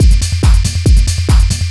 DS 140-BPM A1.wav